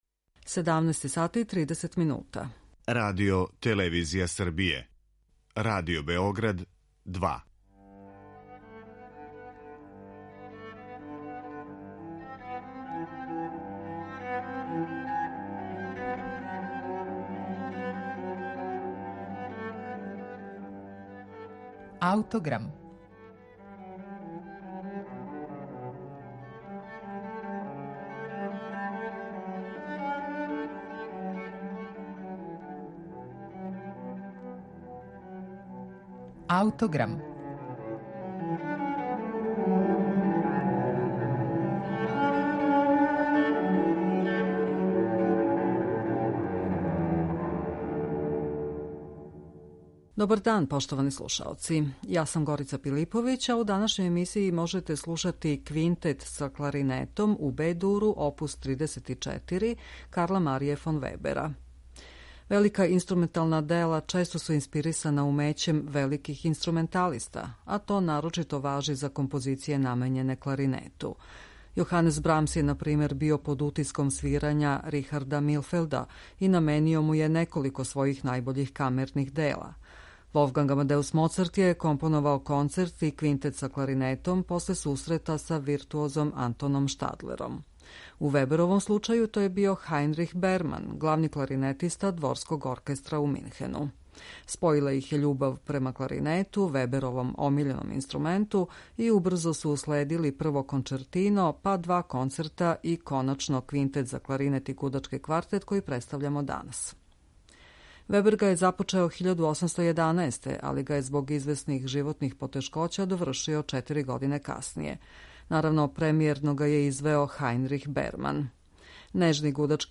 Спојила их је љубав према кларинету, Веберовом омиљеном инструменту и убрзо су уследили прво кончертино, па два концерта и коначно Квинтет за кларинет и гудачки квартет који представљамо данас.